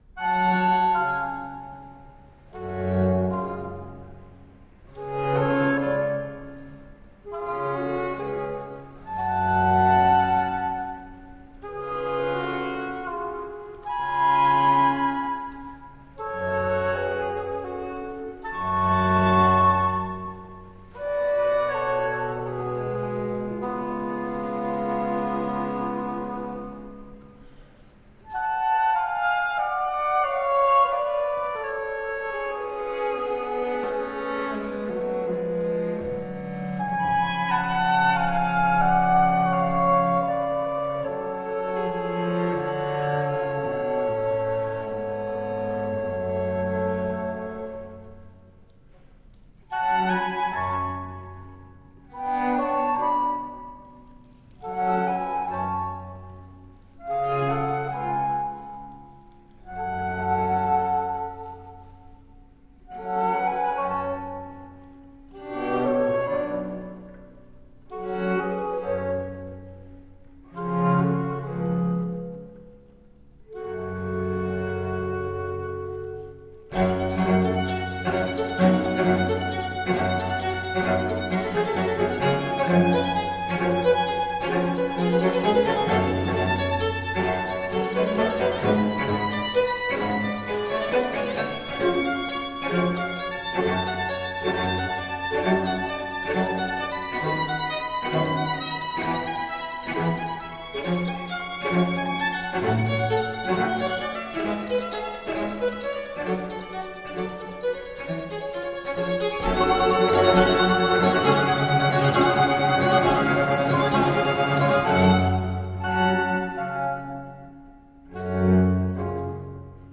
Spectacular baroque and classical chamber music.